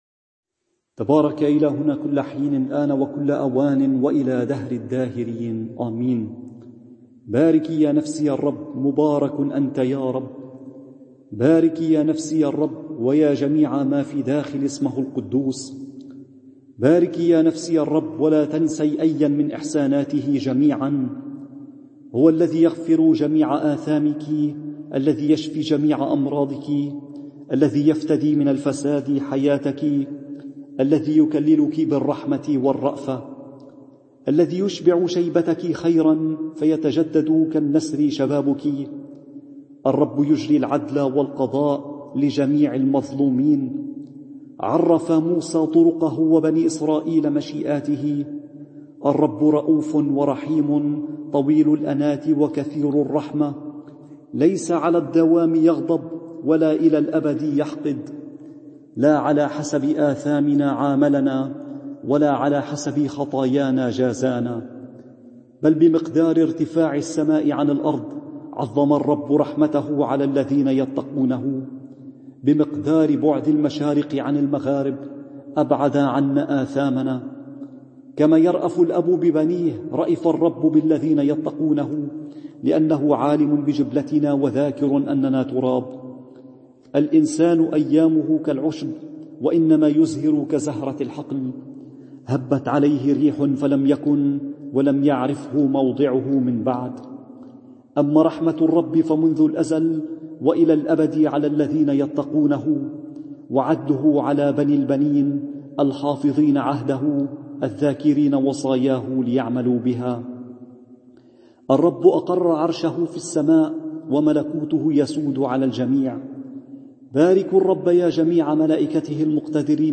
صلاة التيبيكا رهبان